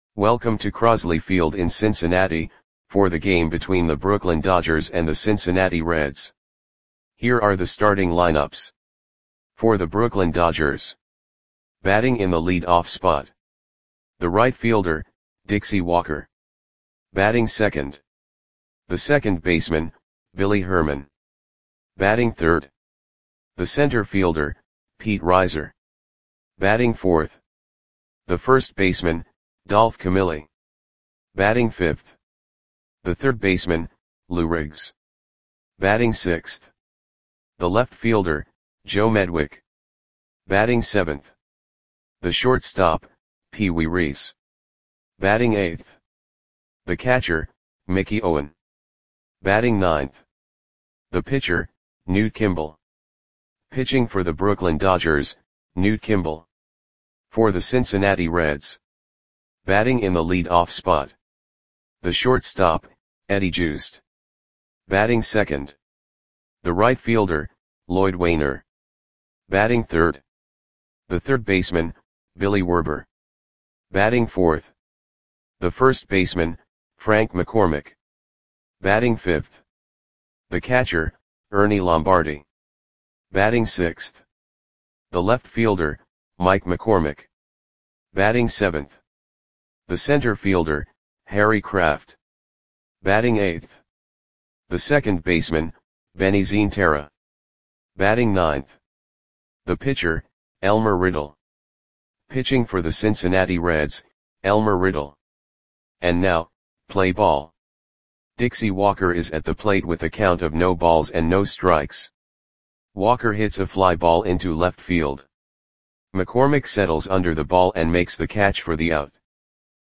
Audio Play-by-Play for Cincinnati Reds on September 16, 1941
Click the button below to listen to the audio play-by-play.